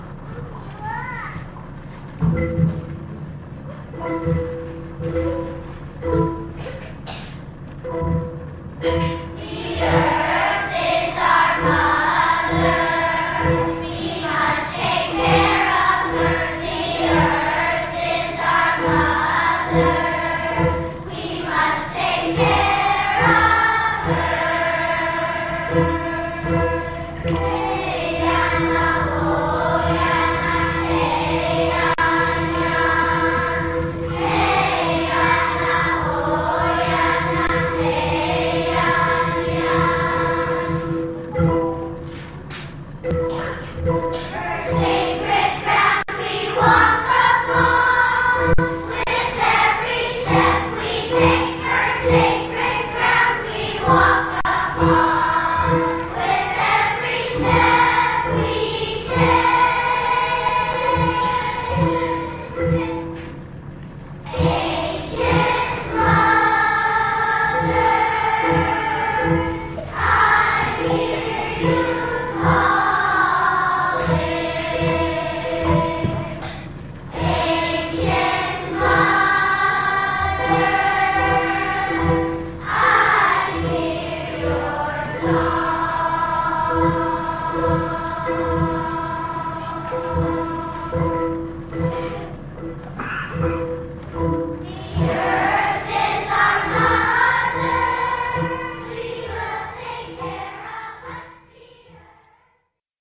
Earth Day Assembly (April) sounds/earhDaySong.mp3 Home • Choir • History of Jazz • History of Western Music • Concert Manners • Audio • Contact